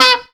PARP.wav